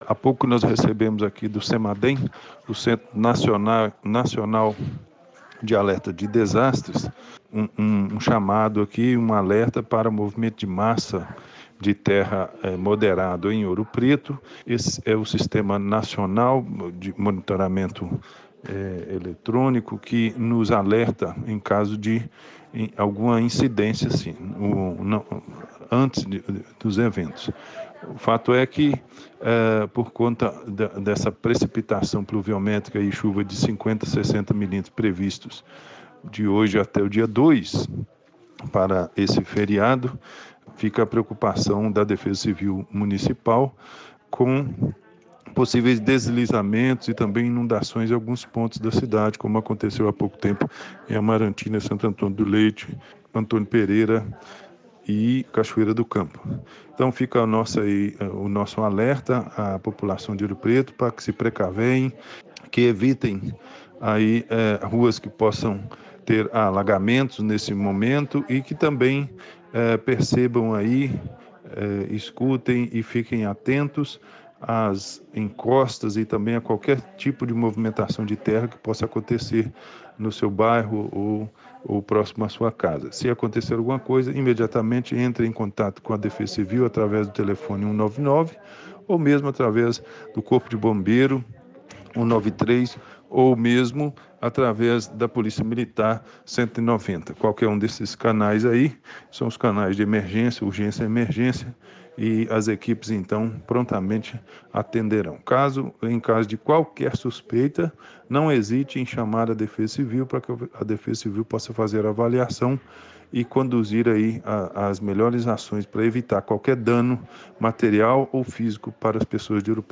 Secretário de Defesa Social alerta sobre o volume de chuvas
Juscelino-Defesa-Civil-nov21.mp3